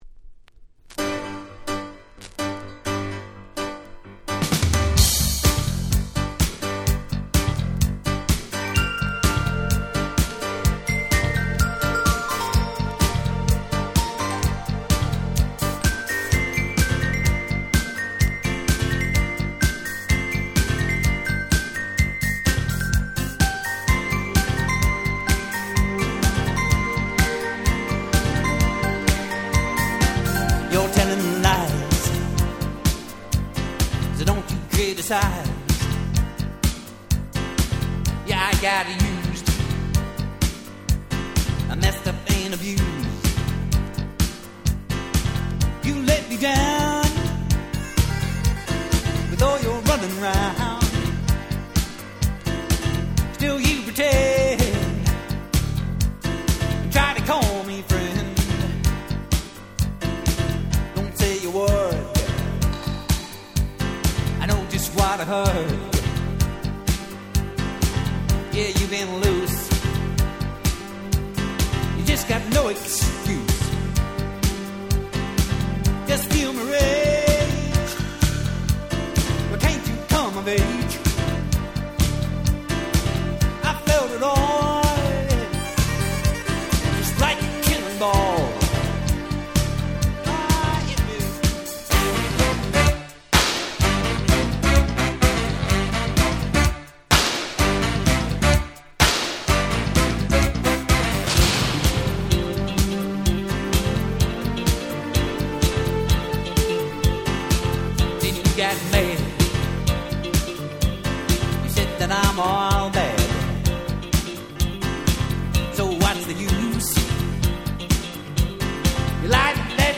85' Super Hit Rock / Pops !!
Disco味もあるPopで最高の1曲です！